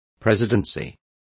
Complete with pronunciation of the translation of presidency.